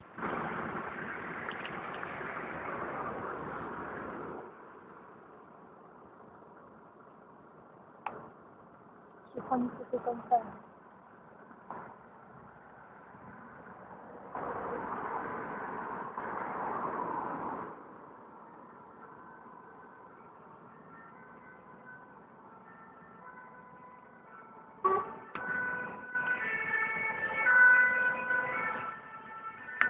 Bruits de sirène et de klaxon